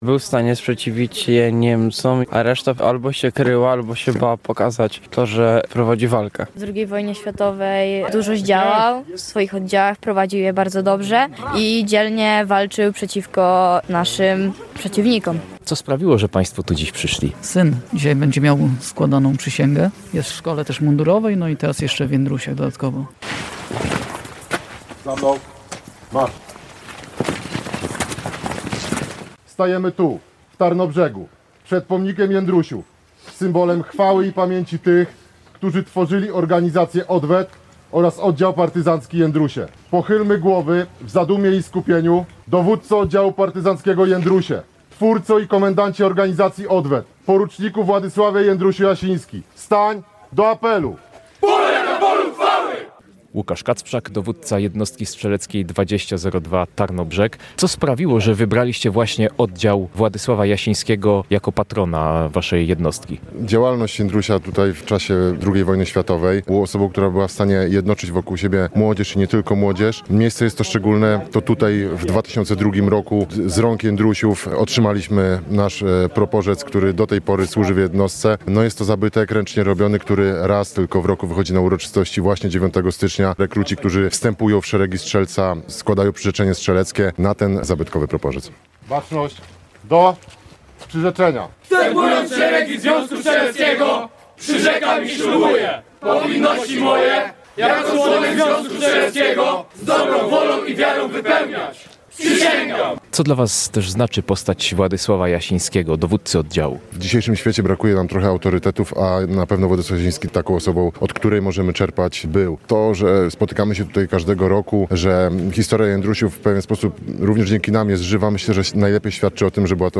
Uroczystości pod pomnikiem na placu Tysiąclecia